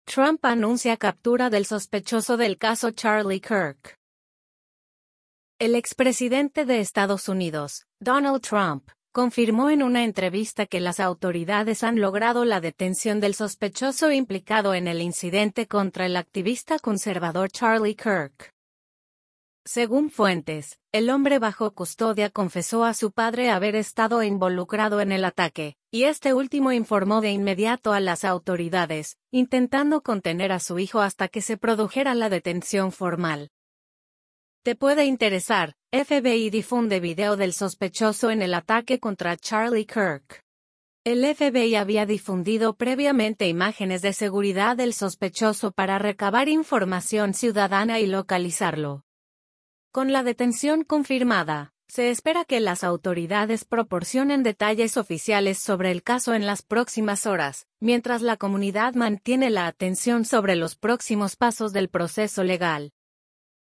El expresidente de Estados Unidos, Donald Trump, confirmó en una entrevista que las autoridades han logrado la detención del sospechoso implicado en el incidente contra el activista conservador Charlie Kirk.